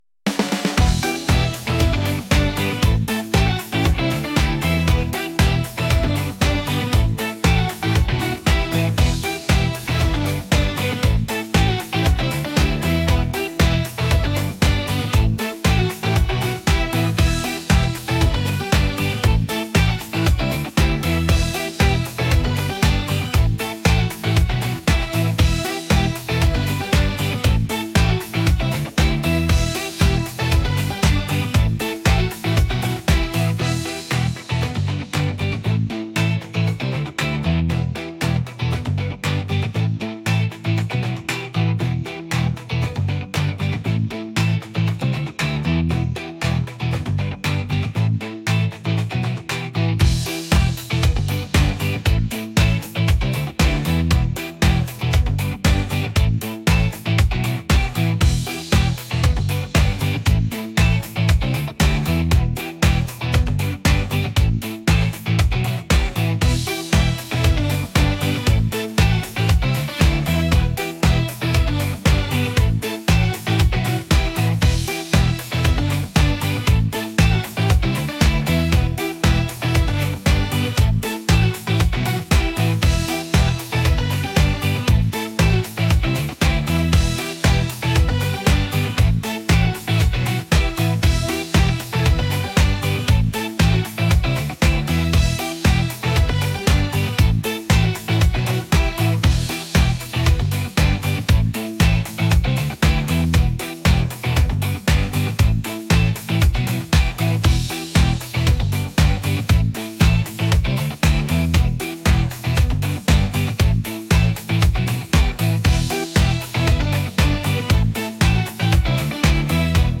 pop | energetic | upbeat